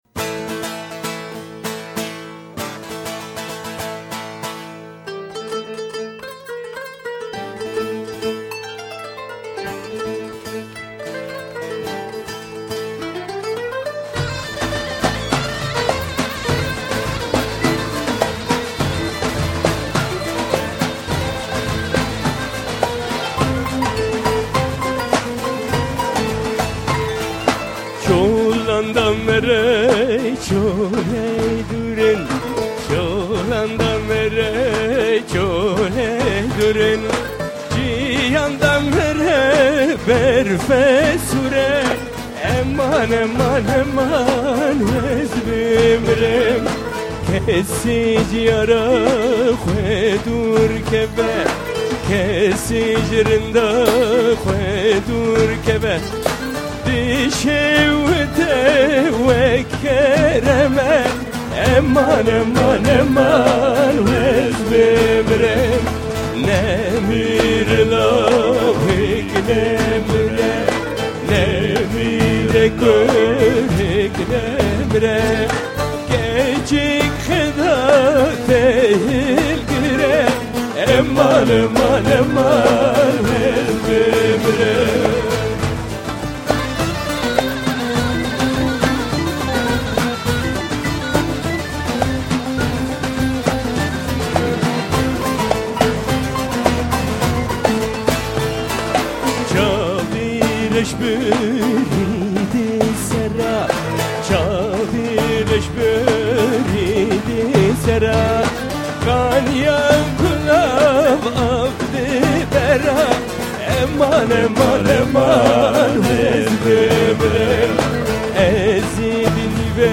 7.94 MB Live aus dem Kammersaal, Graz. SBD-Aufnahme